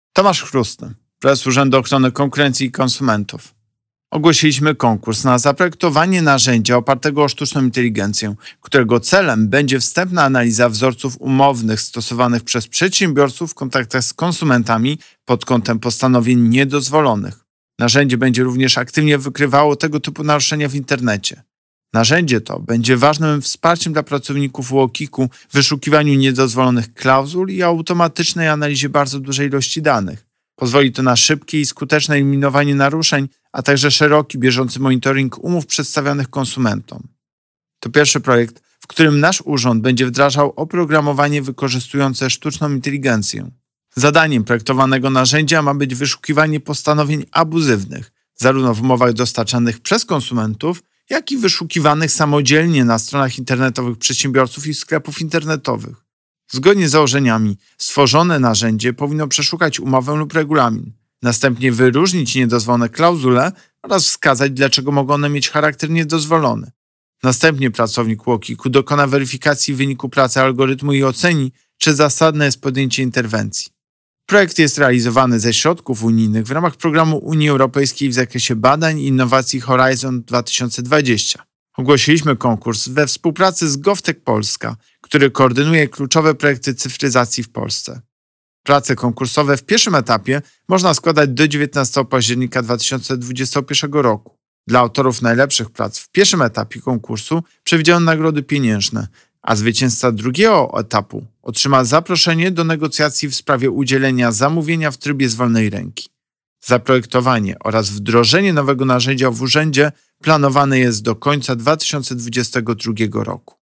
Pobierz wypowiedź Prezesa UOKiK Tomasza Chróstnego